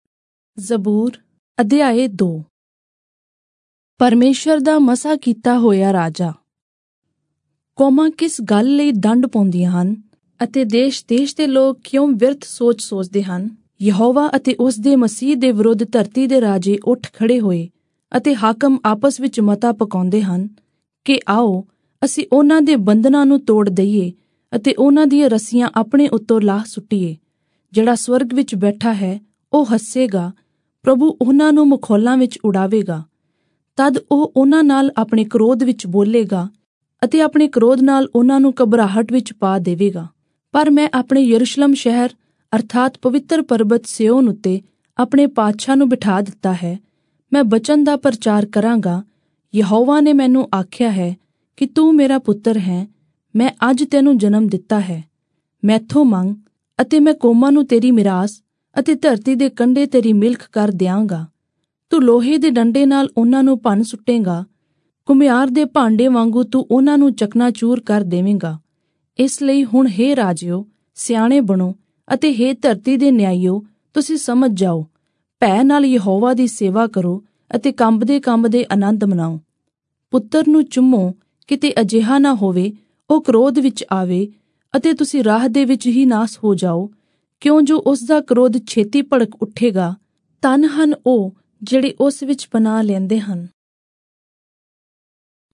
Punjabi Audio Bible - Psalms 1 in Irvpa bible version